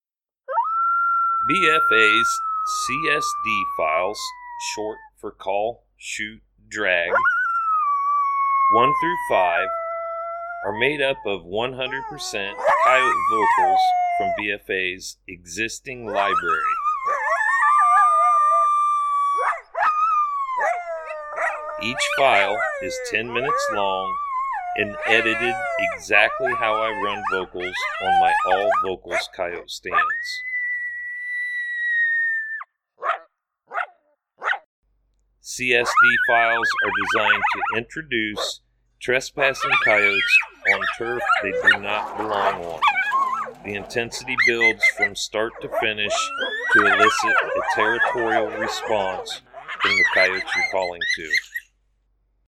Each BFA CSD File is 10 minutes in length, made up from our most popular Coyote Howls, Coyote Social Vocalizations and Coyote fights.